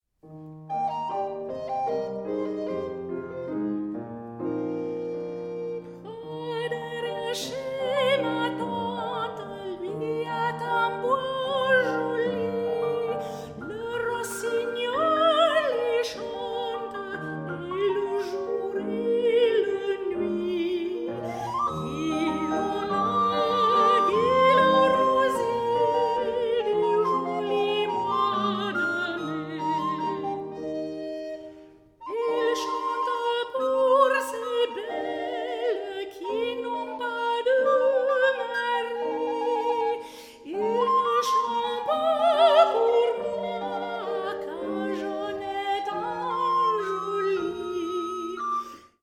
for soprano, recorder and piano